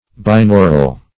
Binaural \Bin*au"ral\, a. [Pref. bin- + aural.]